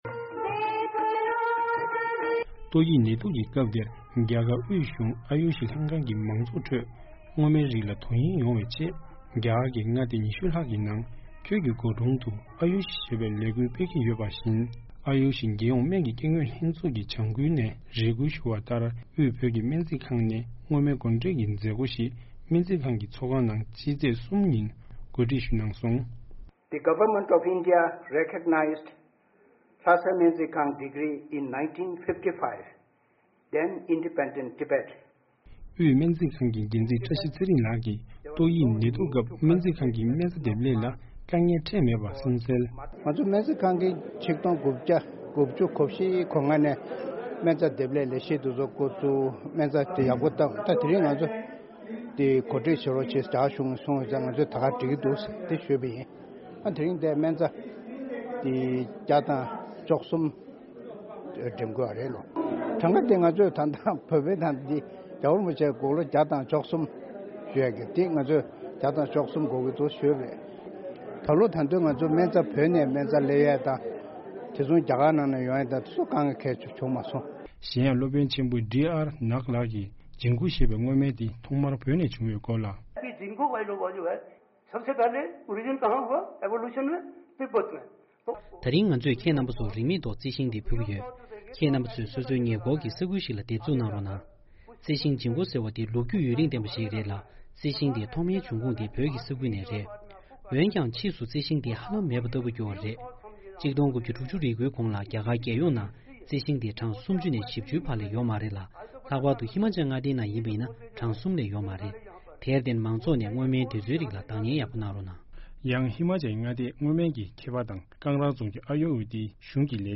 ༄༅།། རྒྱ་གར་དབུས་གཞུང་ཨ་ཡུ་ཤ་ལྷན་ཁང་གི་ལས་གཞིའི་འོག་དབུས་སྨན་རྩིས་ཁང་གིས་སྔོ་སྨན་བགོ་འགྲེམ་བྱེད་ཀྱི་ཡོད་པའི་སྐོར། བཞུགས་སྒར་རྡ་རམ་ས་ལ་ནས་ཨ་རིའི་རླུང་འཕྲིན་ཁང་གི་གསར་འགོད་པ